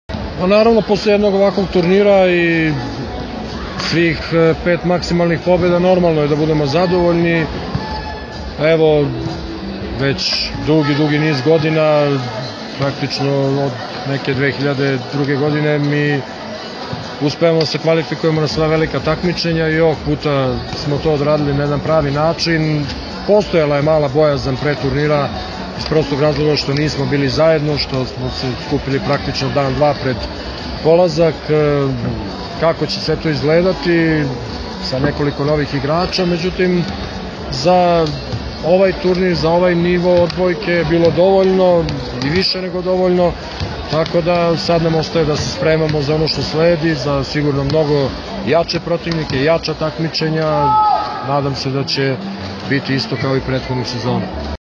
IZJAVA ZORANA TERZIĆA